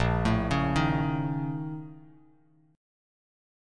BbmM7bb5 chord